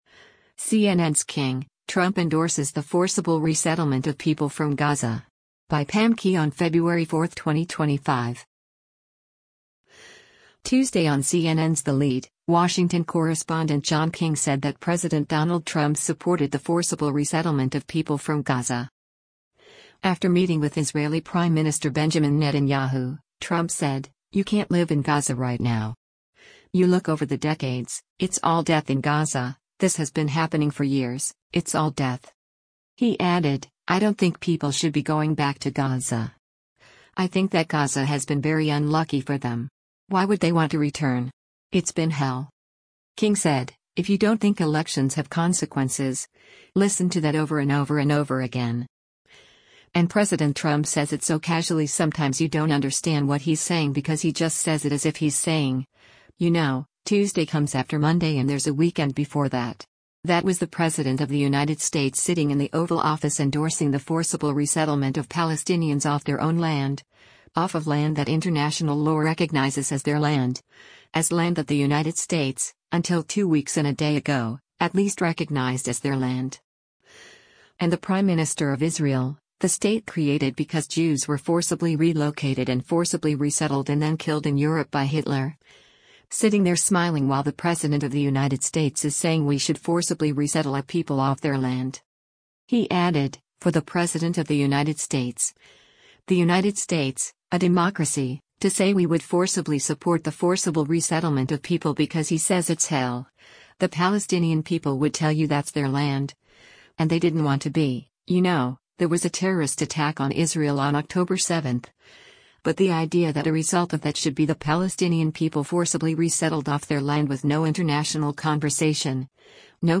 Tuesday on CNN’s “The Lead,” Washington correspondent John King said that President Donald Trump supported “the forcible resettlement of people” from Gaza.